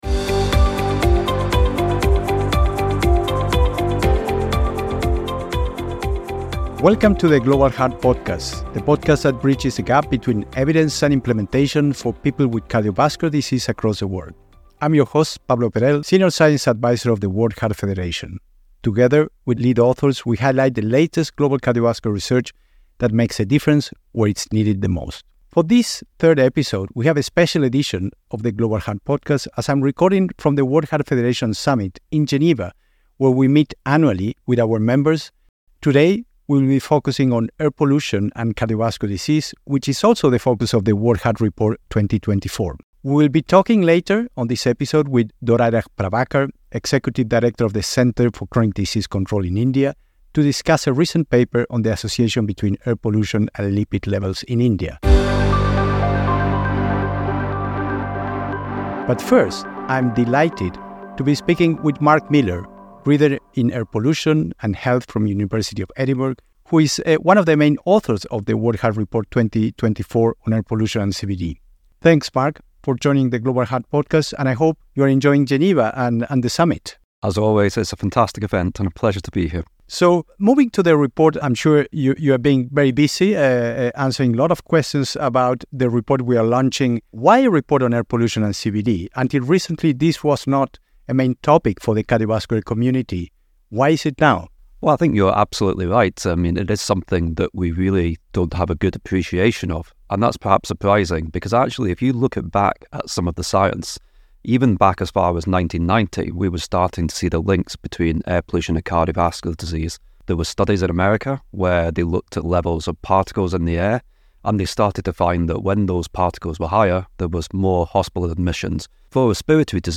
Recorded live from the World Heart Federation Summit in Geneva, this episode features insights from leading researchers working to bridge the gap between scientific evidence and implementation in heart health worldwide.